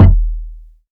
Kicks
KICK.70.NEPT.wav